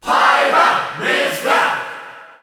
Crowd cheers (SSBU)
Pyra_&_Mythra_Cheer_Russian_SSBU.ogg